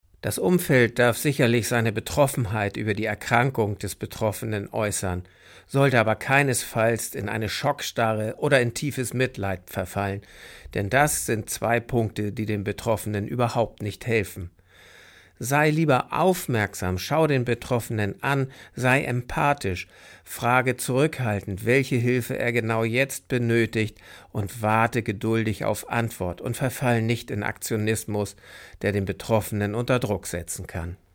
radioEXPERTEN - Ihr perfekter Interviewpartner